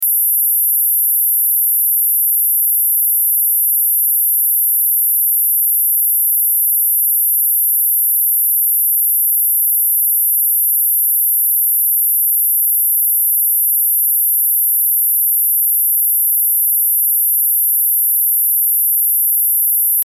Sonnerie audibles par tous, harmoniques du 440 Hz, à partir du 25°, soit 11 000 Hz, au format MP3.